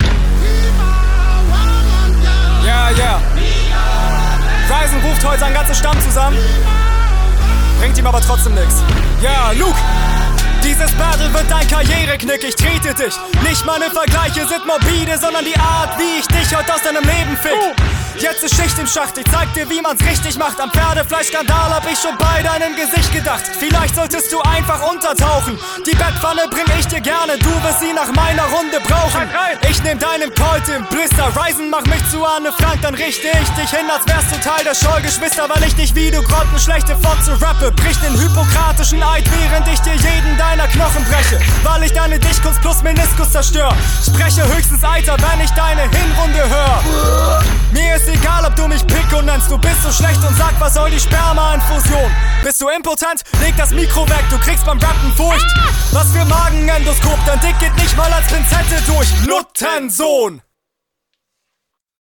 Flow: Schöner Stimmeinsatz, ziemlich stilsicher.